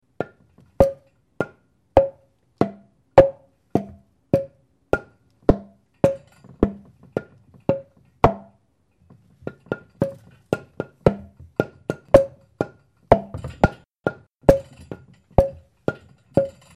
During February 2008 I made a new handmade instrument each day.
woodengong.mp3